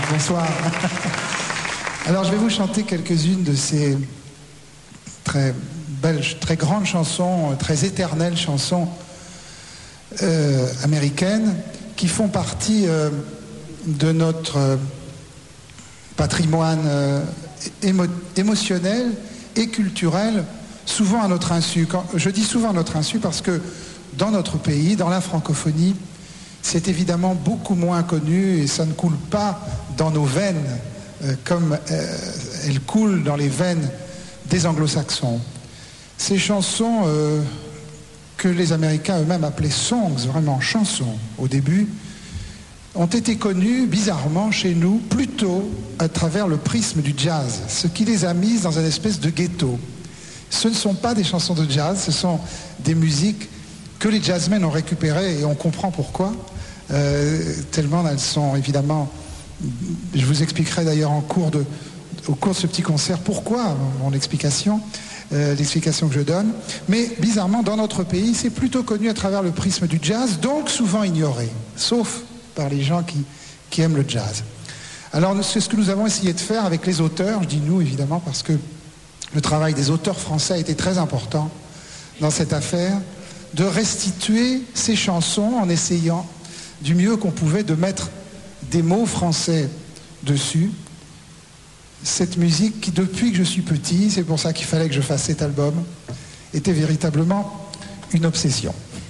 Concert privé